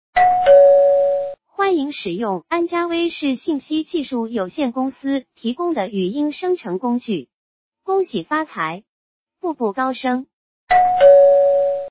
好评不断，安佳威视文本语音转换工具再次升级
今天，我司的文本语音转换工具再次升级，增加了背景音乐功能，还可以在语音前后叠加您中意的提示音乐！